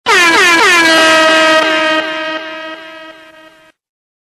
Category: Siren Sounds